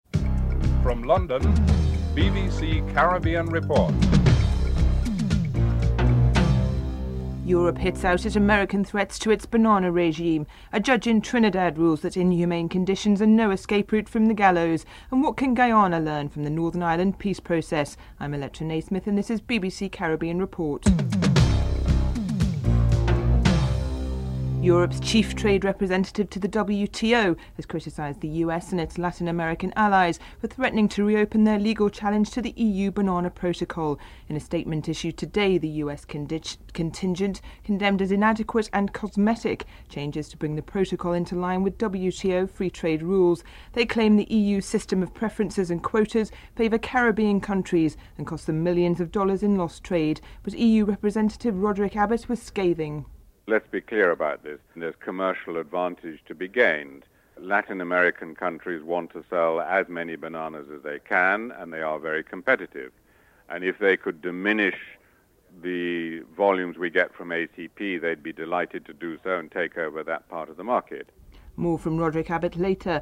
EU trade representative Roderick Abbott is interviewed (02:15-05:06)